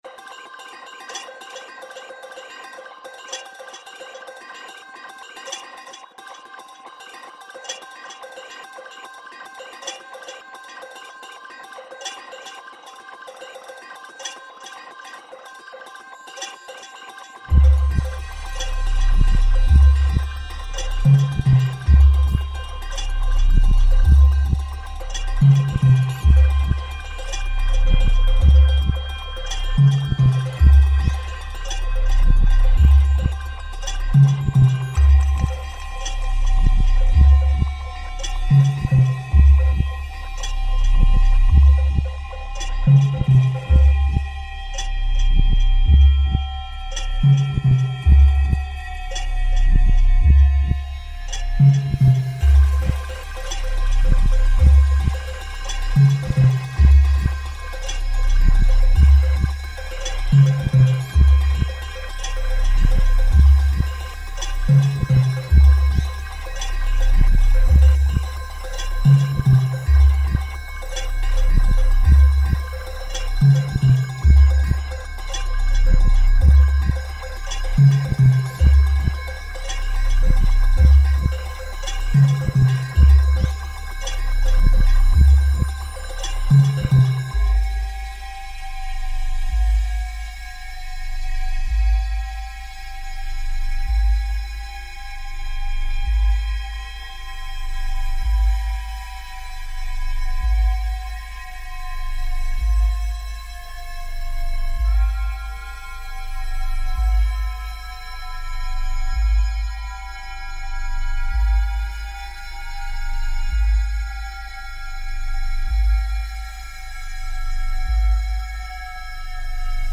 Cows in the Dolomites reimagined